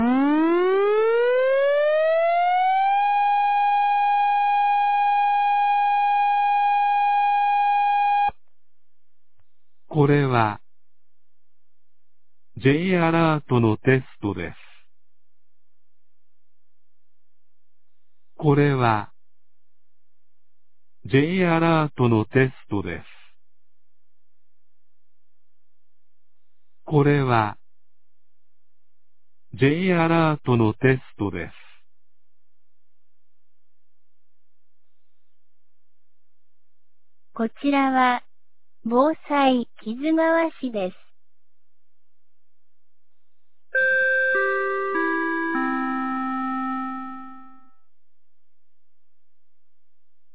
2025年05月28日 11時01分に、木津川市より市全域へ放送がありました。
放送音声